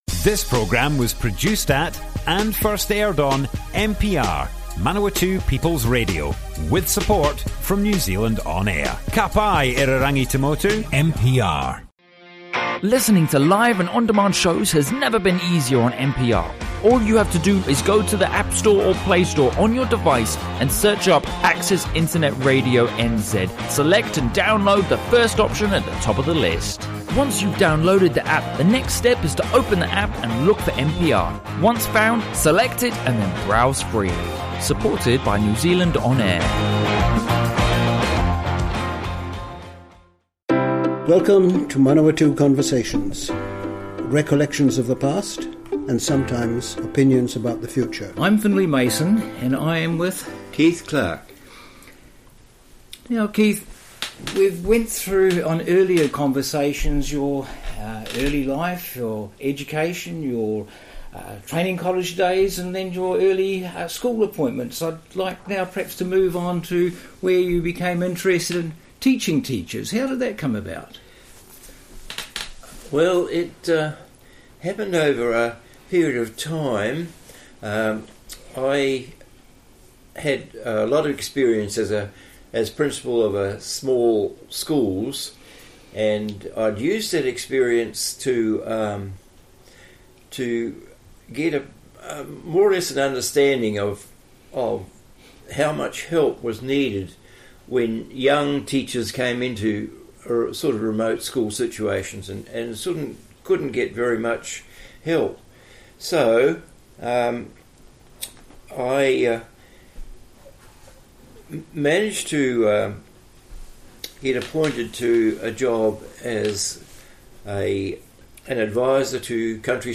Broadcast on Manawatū People's Radio, 8th October 2019.